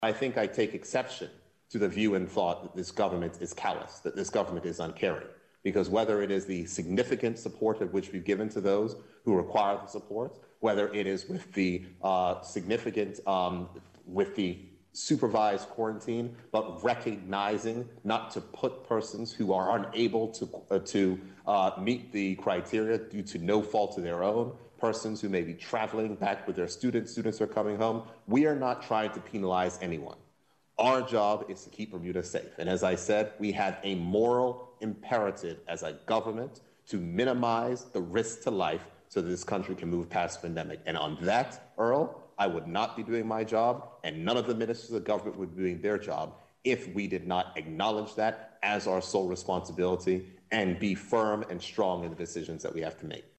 This from Premier David Burt during the most recent national pandemic briefing.